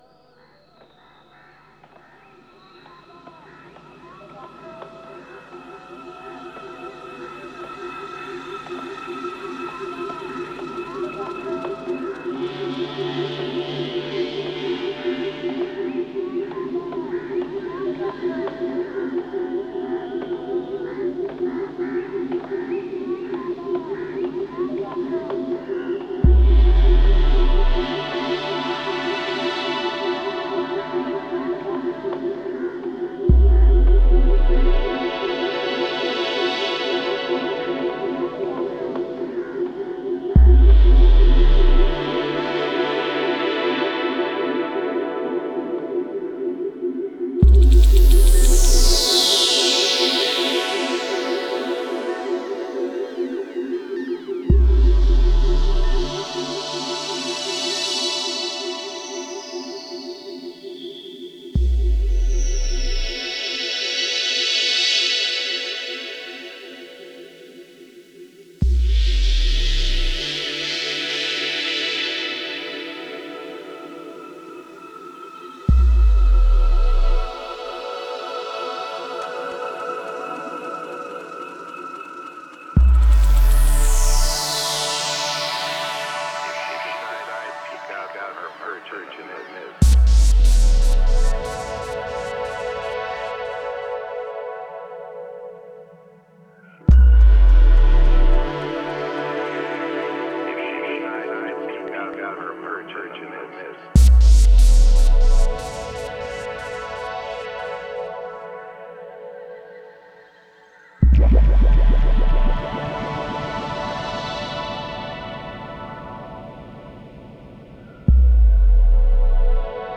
Genre: Deep Ambient.